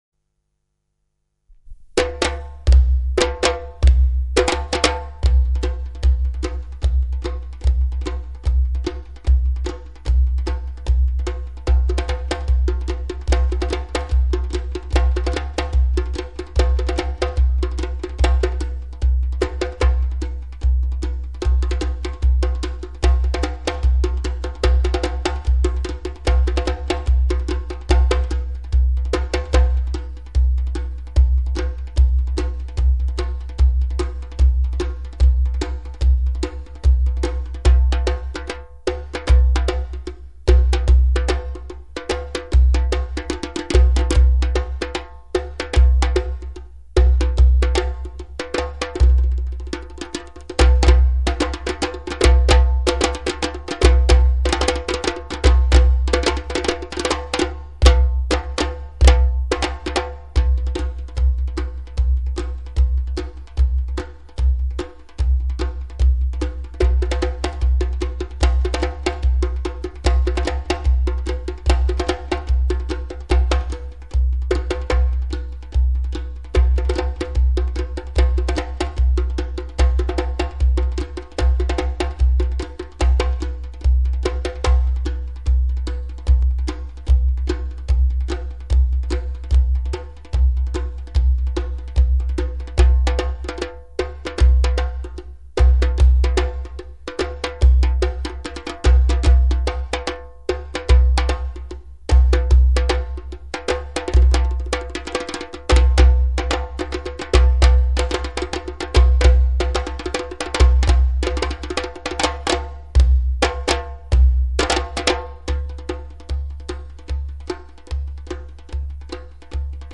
drumming samples
drum samples, click to listen